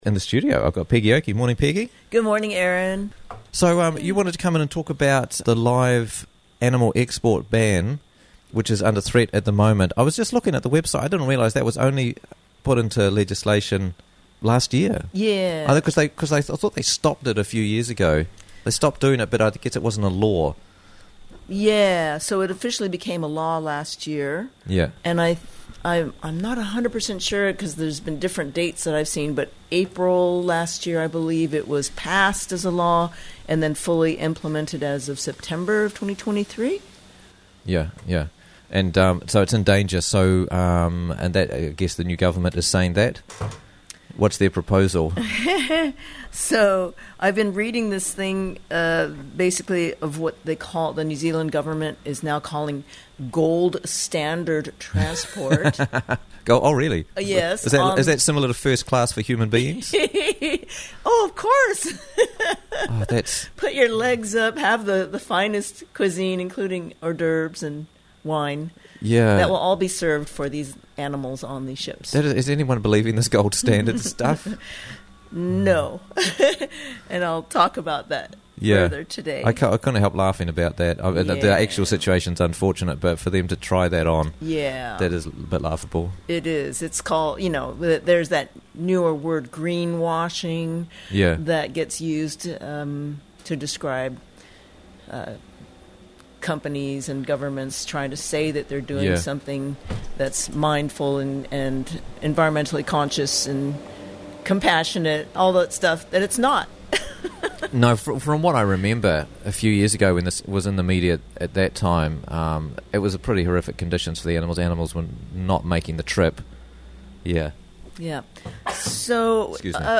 Live Animal Export Campaign - Interviews from the Raglan Morning Show